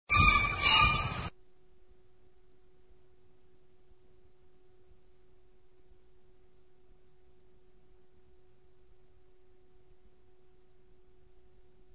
西五番では、グリーン近くにキジが現れ、「ケン、ケン
」と縄張りを主張していた。
kiji.mp3